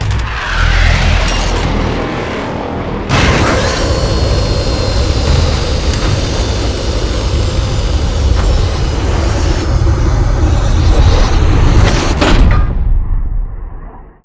land.ogg